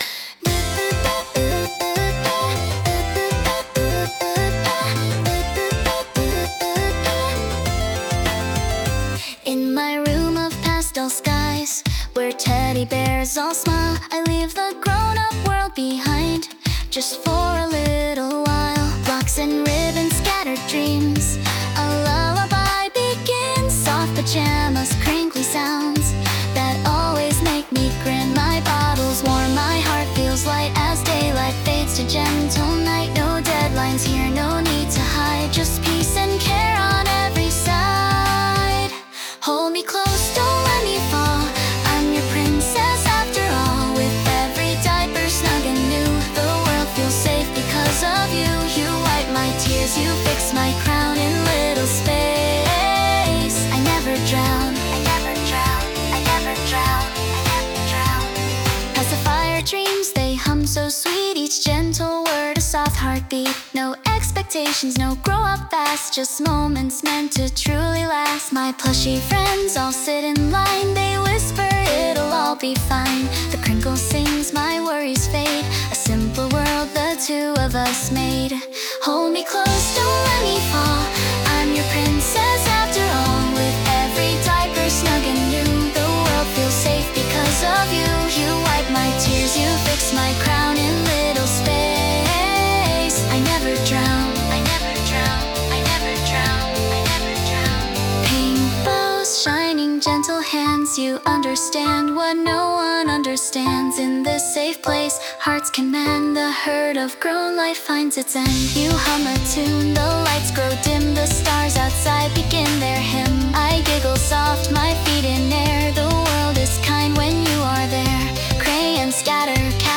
Genre: Pop
Vocal: KI (Suno Premier)